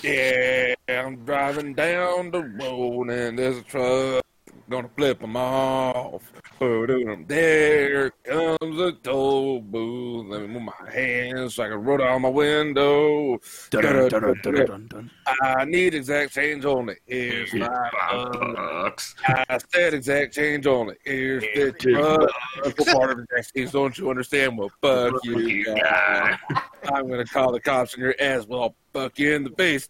File: singalong time.swf-(330 KB, 550x400, Loop)
Based weedman singing roadtrip.swf: